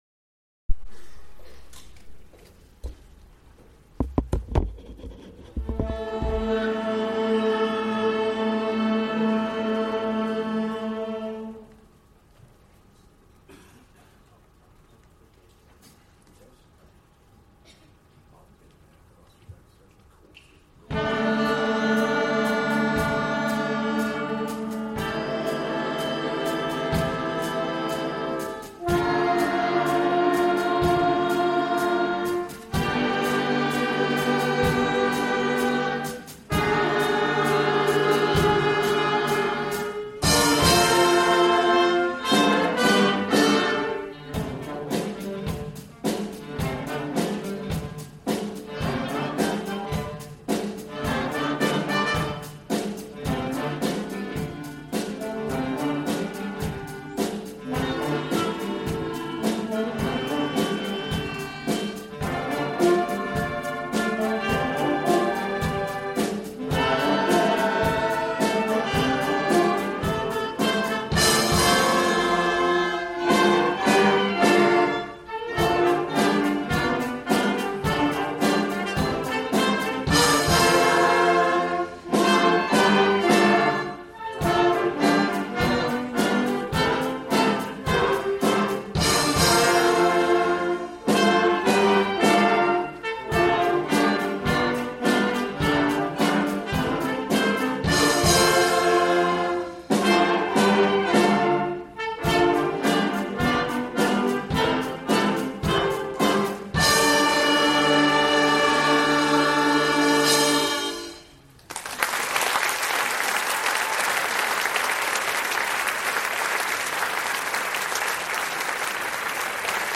Junior and Primary Wind Bands
Summer Concert 2014